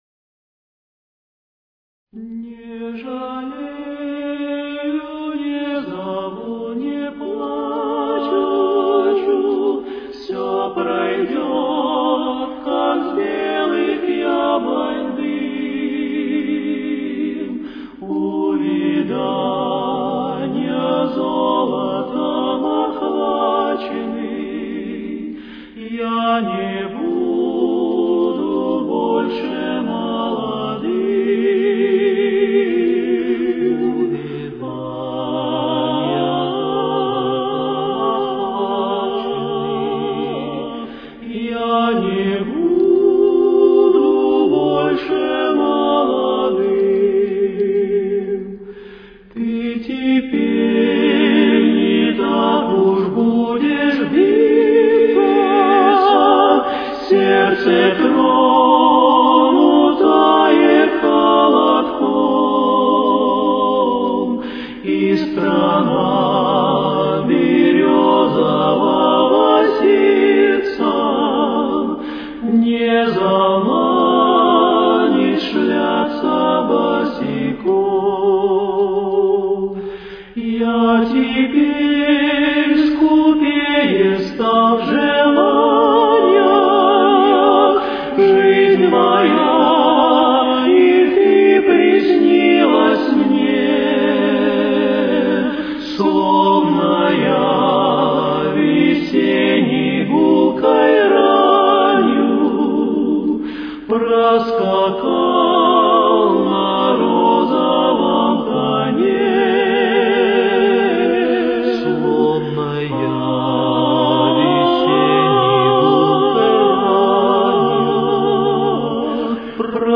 с очень низким качеством (16 – 32 кБит/с)
Тональность: Фа-диез минор. Темп: 63.